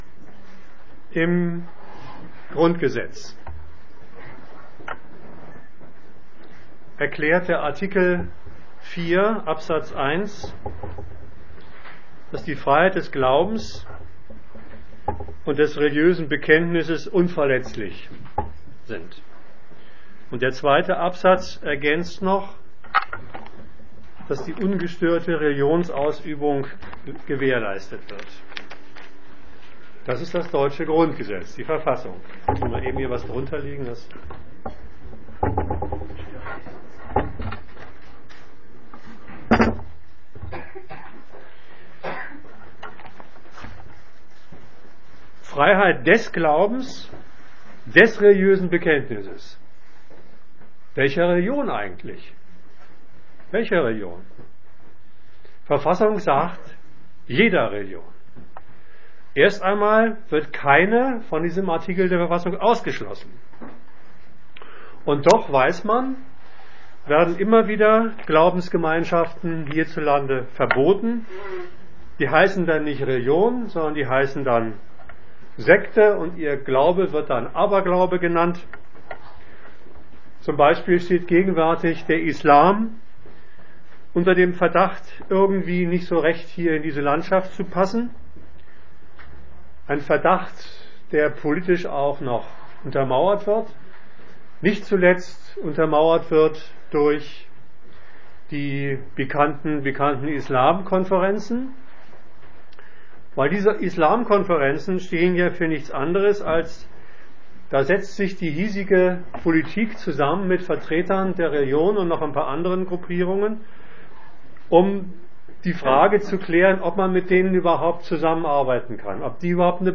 Gliederung des Vortrags: Teil 1.